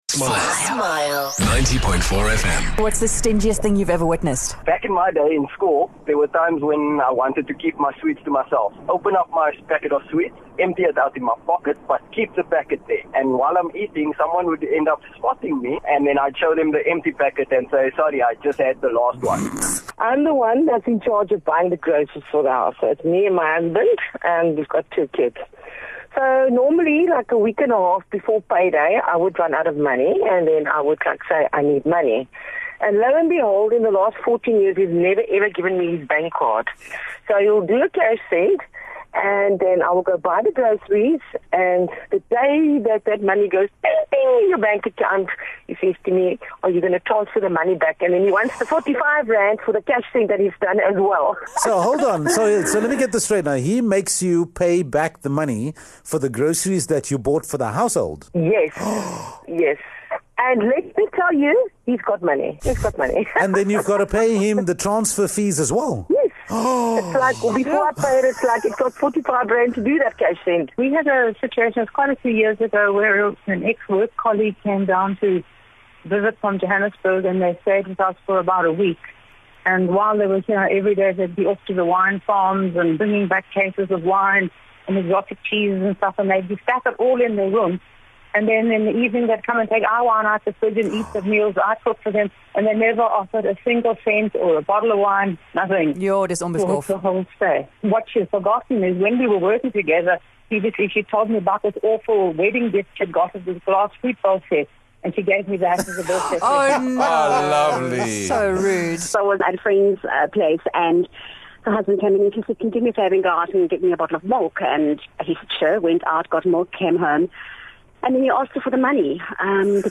The Smile Breakfast team asked our listeners to call in and tell us about the stingiest behaviour they've ever done or witnessed. We were most surprised by some of the ways two of our listeners' husbands were with money